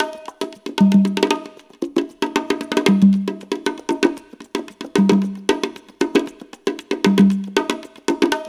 CONGA BEAT32.wav